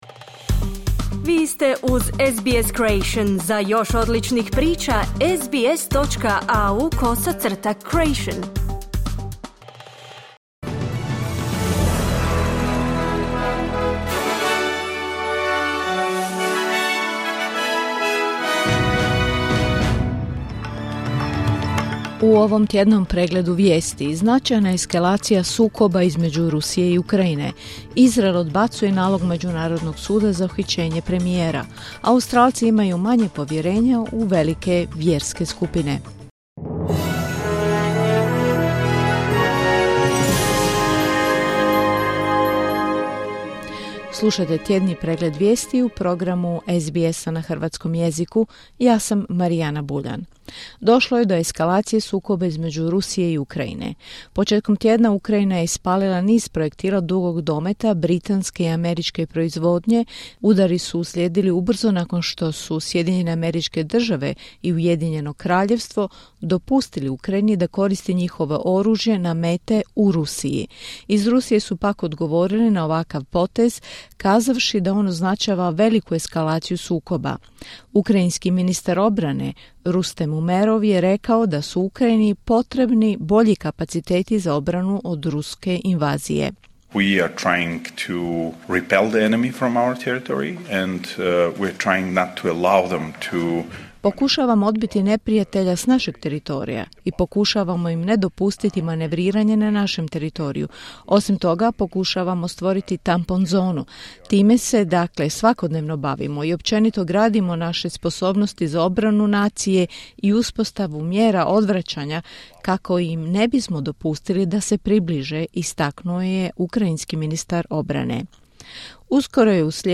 Tjedni pregled vijesti. Emitirano uživo na radiju SBS1 u 11 sati.